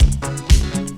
DISCO LOO02R.wav